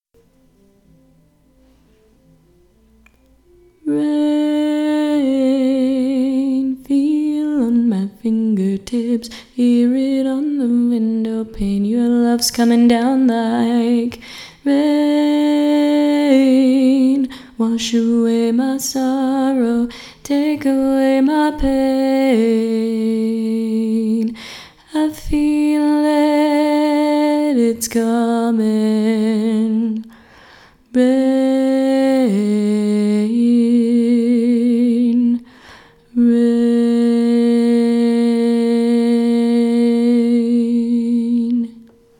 :-P I also apologize for the quality of the tracks.
Each recording below is single part only.